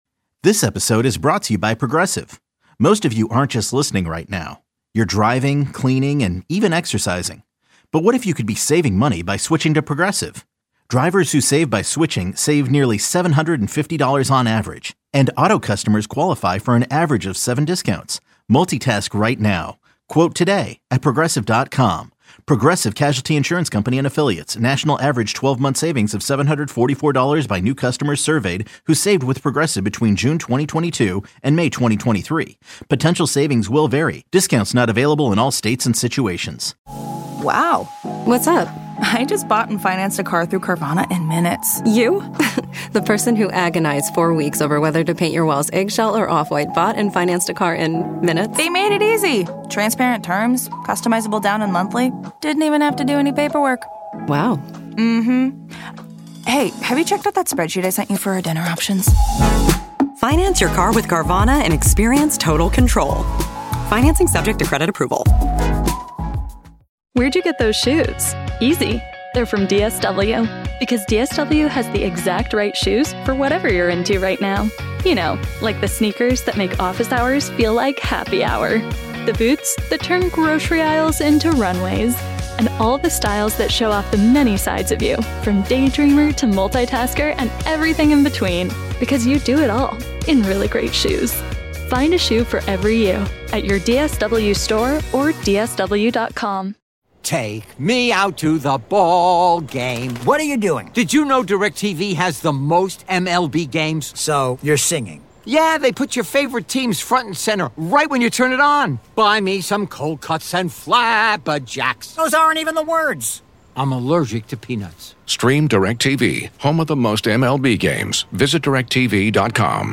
Best of 92-9 the Game Interviews